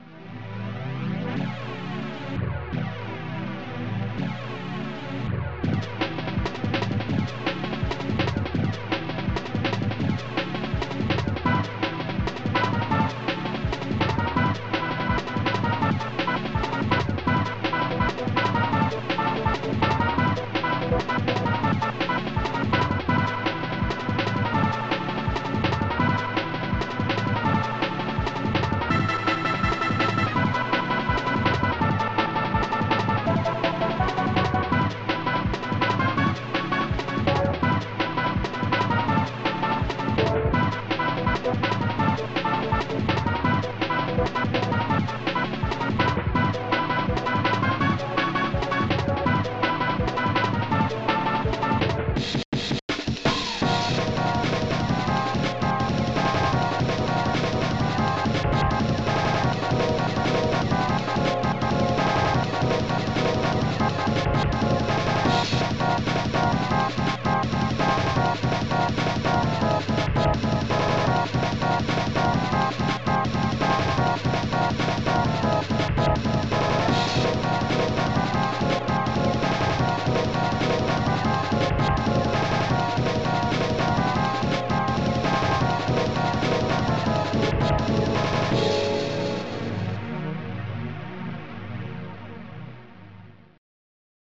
be warned like most ofthis shit is just dnb/jungle